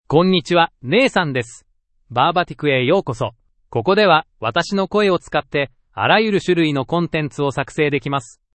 NathanMale Japanese AI voice
Nathan is a male AI voice for Japanese (Japan).
Voice sample
Listen to Nathan's male Japanese voice.
Nathan delivers clear pronunciation with authentic Japan Japanese intonation, making your content sound professionally produced.